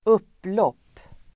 Uttal: [²'up:låp:]